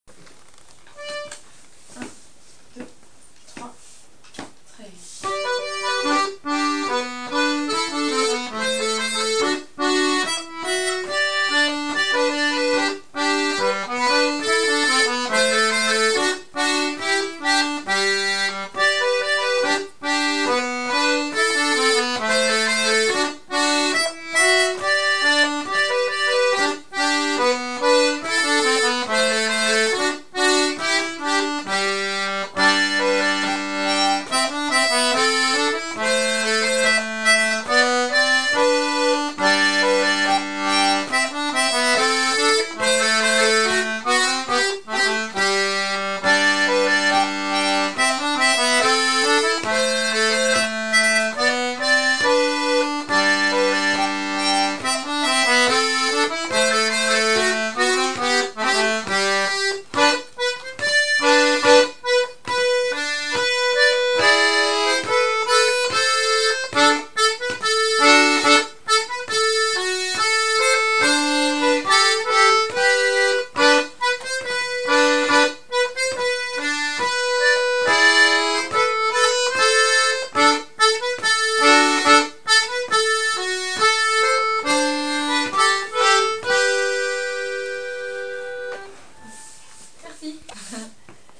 l'atelier d'accordéon diatonique
travail sur Juokin Toinen Jenkka, scottish en cortège finlandaise